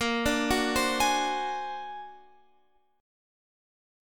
A# Augmented 9th